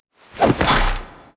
skill_freeze_a.wav